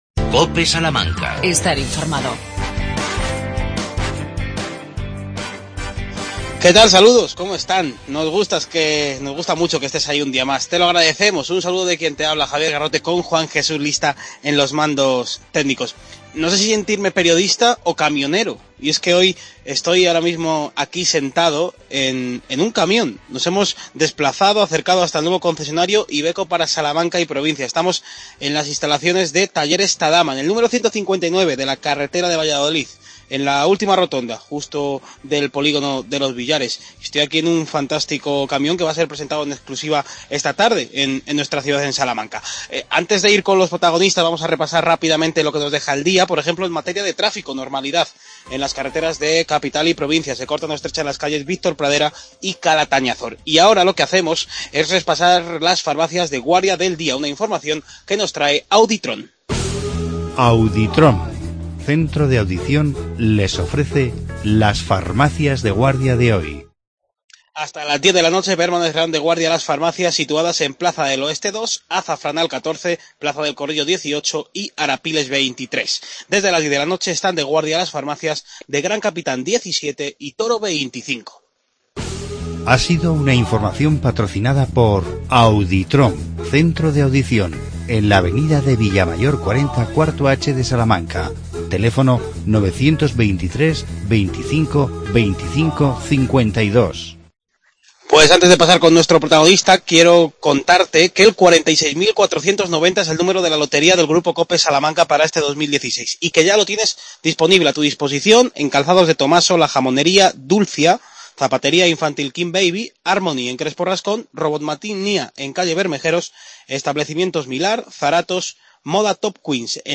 Redacción digital Madrid - Publicado el 18 nov 2016, 13:03 - Actualizado 02 oct 2018, 17:33 1 min lectura Descargar Facebook Twitter Whatsapp Telegram Enviar por email Copiar enlace Programa especial desde el nuevo concesionario IVECO para Salamanca y provincia.